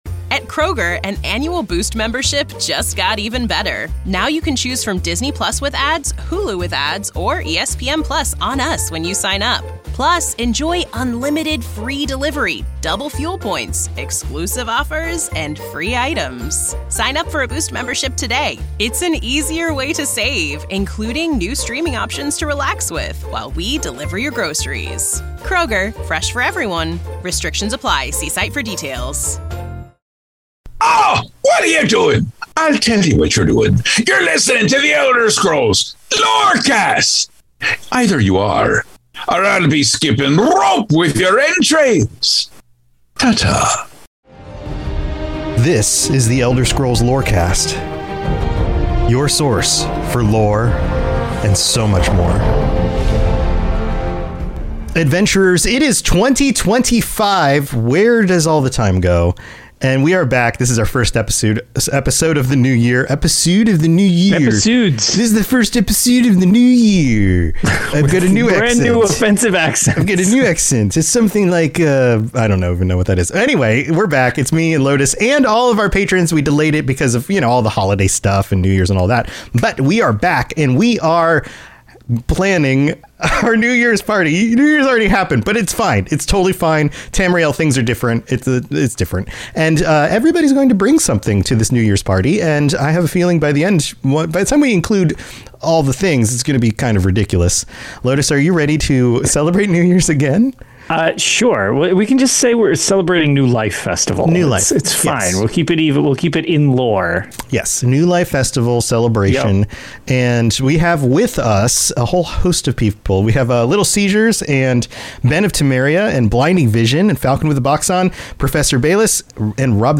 The Elder Scrolls universe explained beginning with perfect bite-sized chunks and evolving into a weekly conversation.